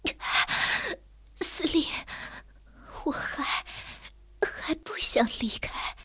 T34-85被击毁语音.OGG